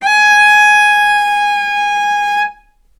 healing-soundscapes/Sound Banks/HSS_OP_Pack/Strings/cello/ord/vc-G#5-ff.AIF at 01ef1558cb71fd5ac0c09b723e26d76a8e1b755c
vc-G#5-ff.AIF